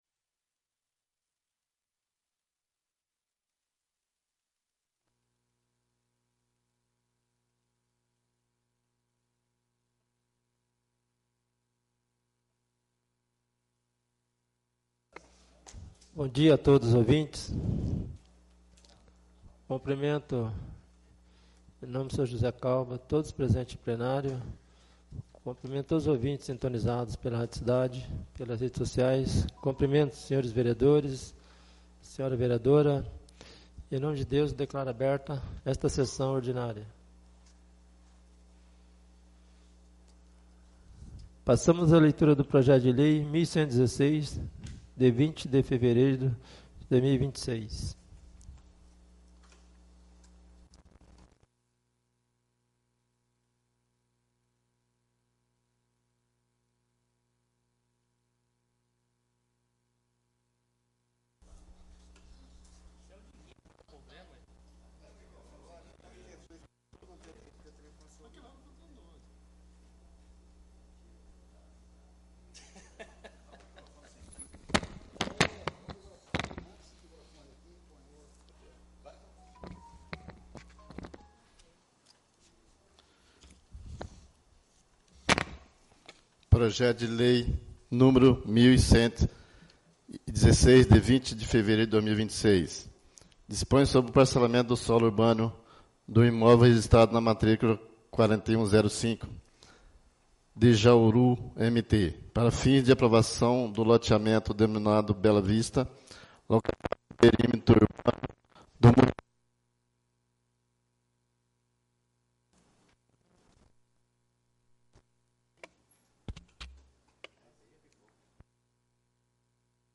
3° SESSÃO ORDINÁRIA DE 02 DE MARÇO DE 2026